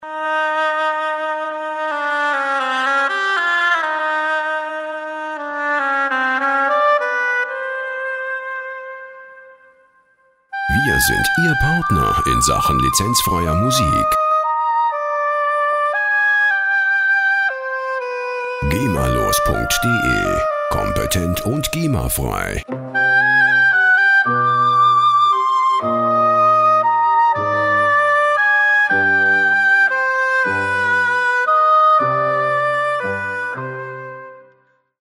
Der Klang der Musikinstrumente
Tempo: 116 bpm